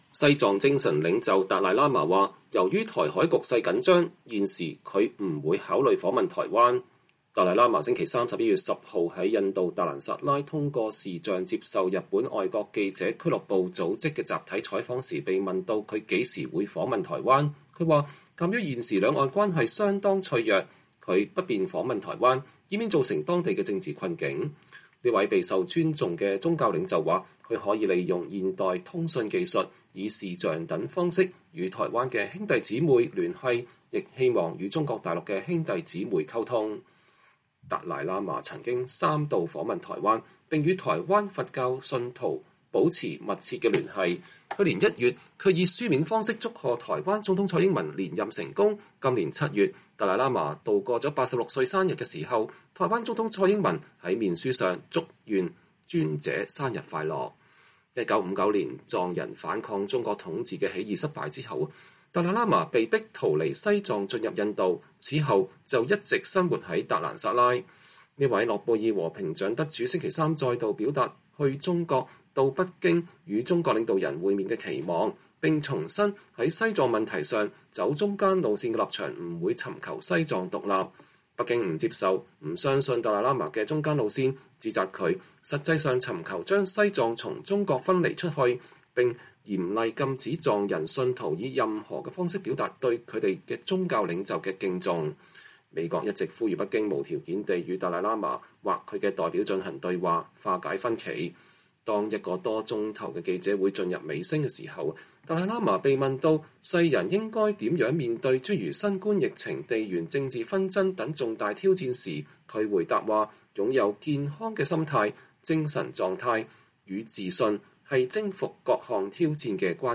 達賴喇嘛星期三（2021年11月10日）在印度達蘭薩拉通過視訊接受日本外國記者俱樂部組織的集體採訪時被問到他何時會訪問台灣。